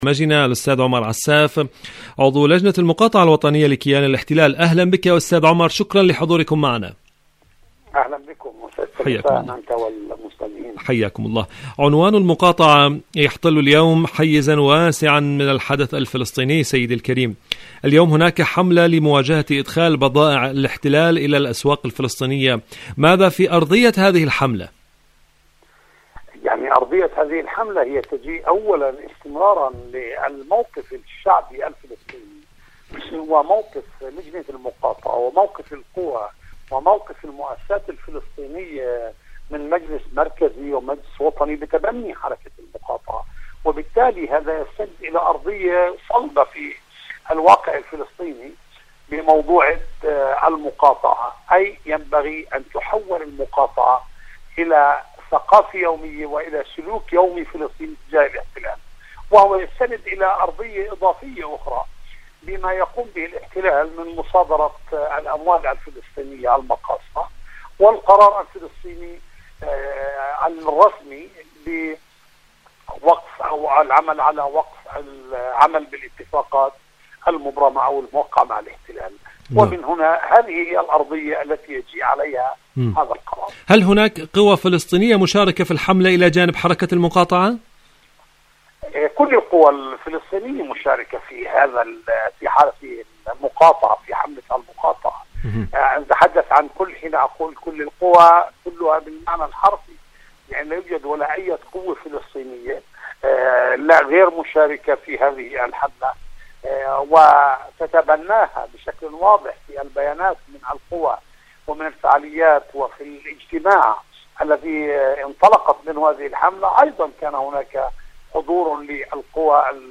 مواجهة اقتصاد الاحتلال فلسطينيا.. مقابلة